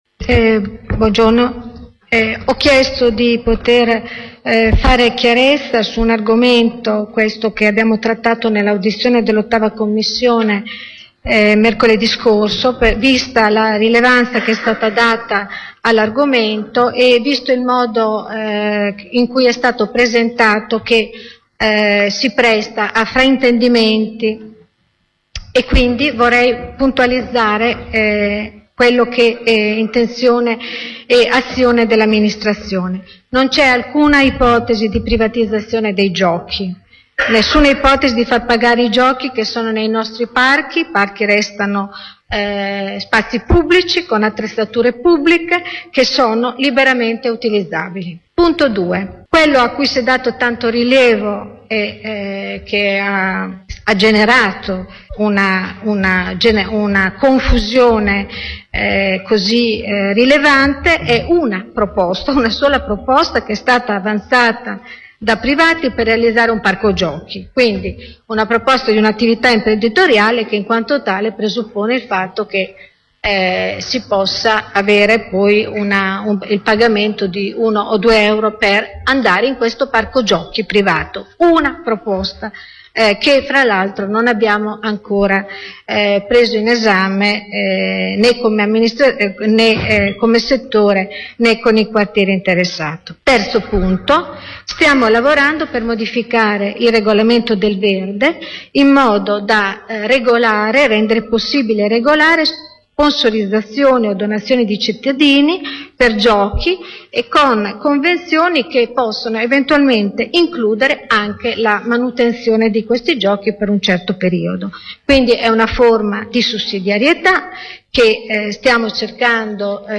L’assessore all’ambiente Patrizia Gabellini è intervenuta all’inizio del Consiglio comunale di oggi per “fare chiarezza” sulla questione giochi nei parchi pubblici cittadini.